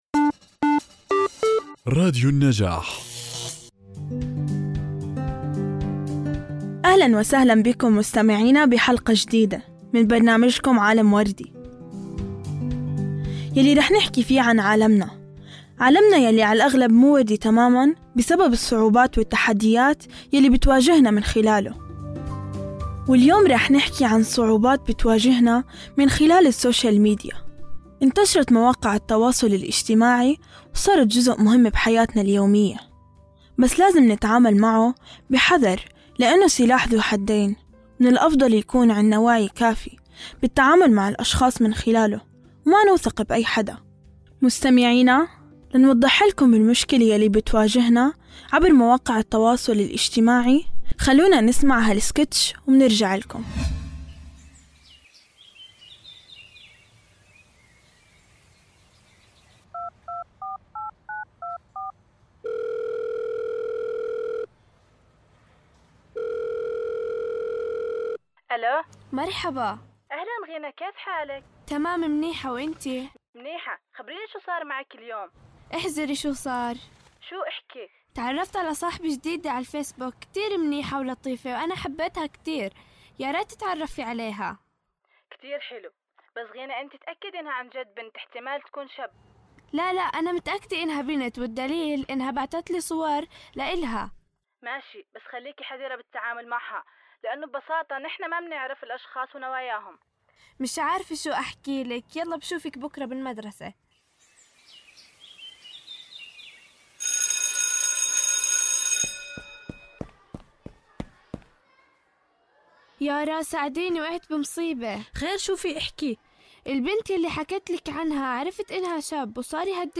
من خلال سكيتش تمثيلي مبتكر ومؤثر، تقدم الحلقة صورة حية للتحديات التي تواجهها الفتيات بسبب السوشال ميديا، يشتمل السكيتش على أحداث وقصص حقيقية تم تجسيدها بطريقة تعكس الواقع الذي يعيشه الكثير من الفتيات والنساء.
بودكاست عالم وردي هو برنامج مميز يقدمه مجموعة من اليافعات المشاركات ضمن مشروع